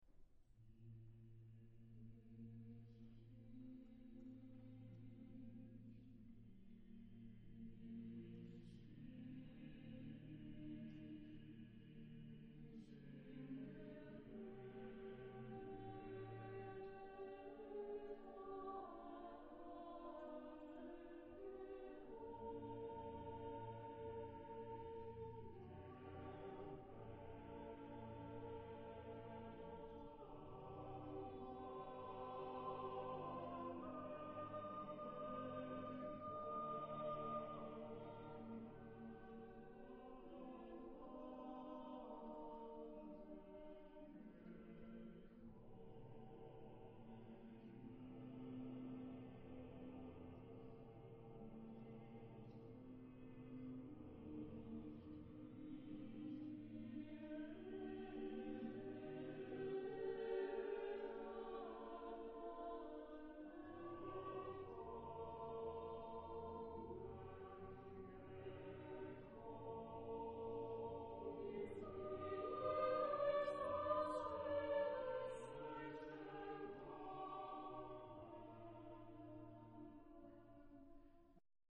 Genre-Stil-Form: Chorwerk
Charakter des Stückes: schwermütig ; langsam
Chorgattung: 4S-4A-4T-4B  (16 gemischter Chor Stimmen )
Tonart(en): Es-Dur